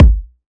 Dilla Kick 45.wav